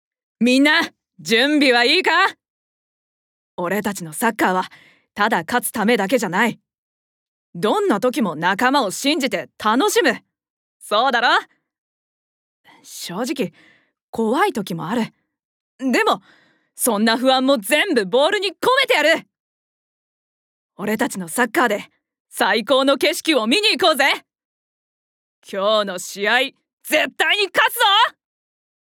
ボイスサンプル
サッカー少年